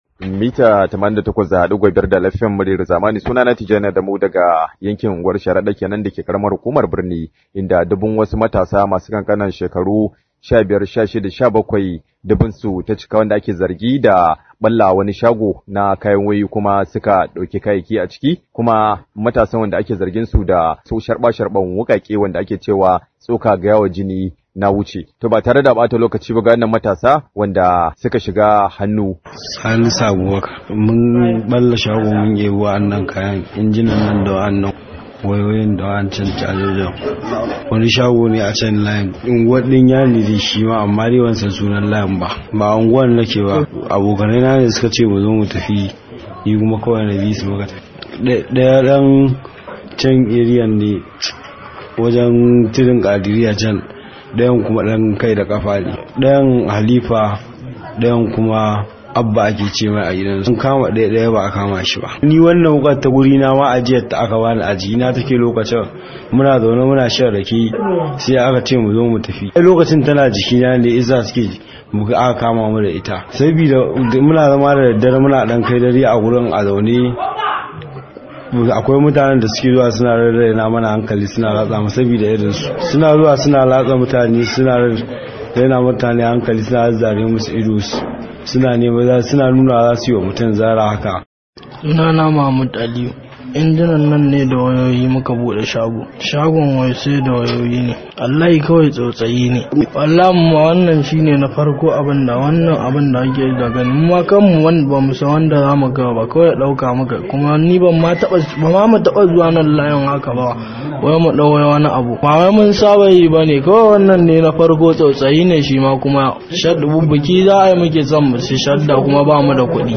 Rahoto: Mun fasa shago saboda Shaddar biki – Matasan da ake zargi